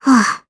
Gremory-Vox_Landing_jp.wav